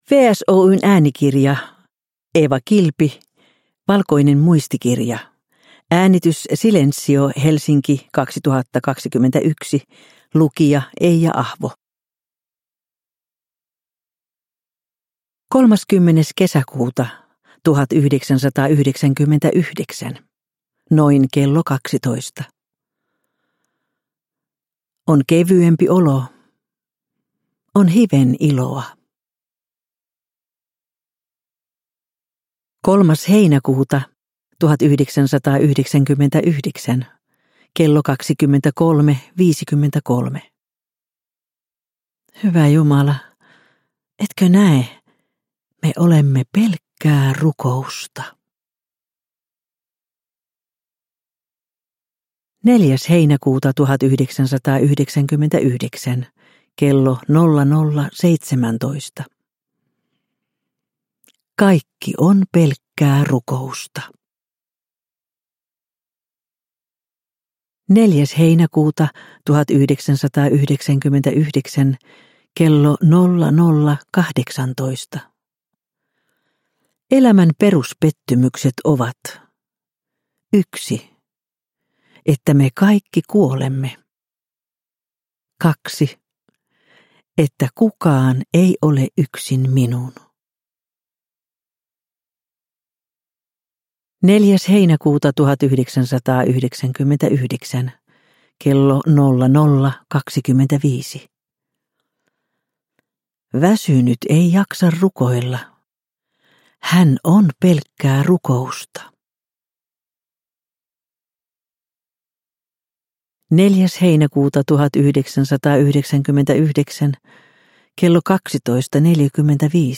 Valkoinen muistikirja – Ljudbok – Laddas ner